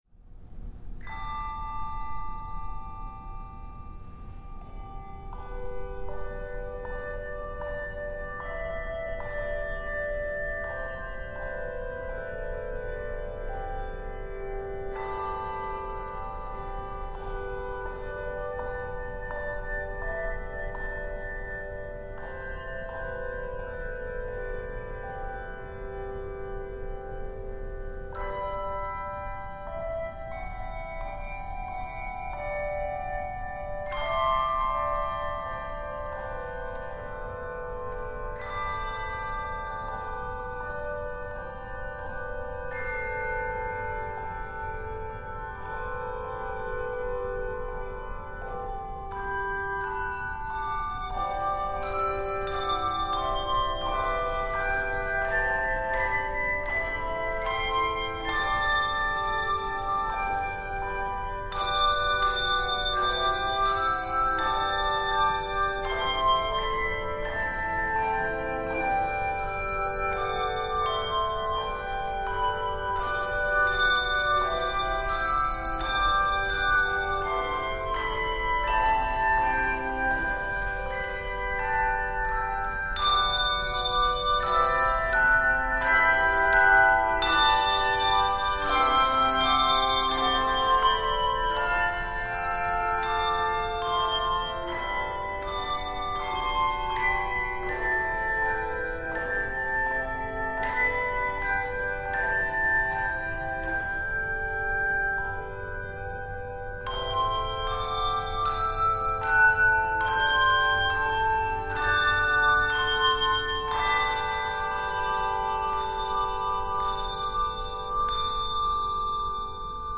Ethereal and delicate